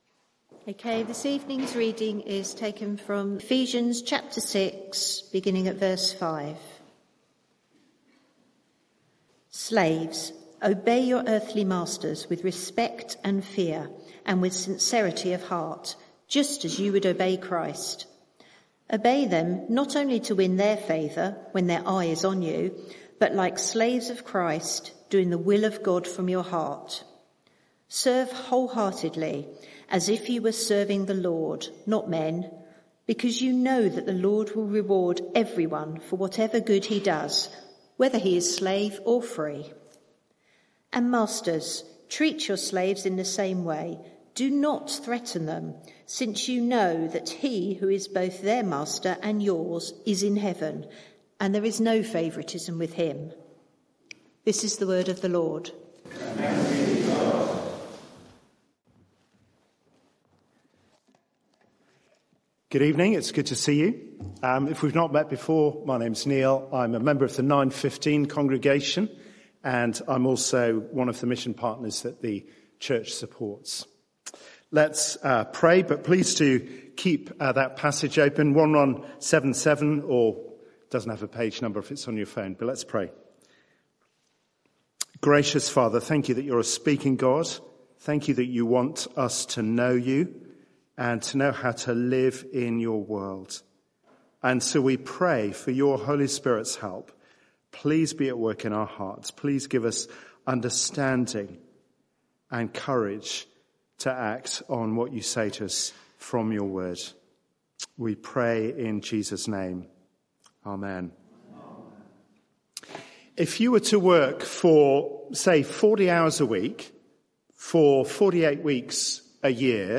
Media for 6:30pm Service on Sun 07th Apr 2024 18:30
Passage: Ephesians 6:5-9 Series: The Power of God for His People Theme: Christians at Work Sermon (audio)